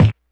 kick03.wav